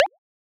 edm-perc-36.wav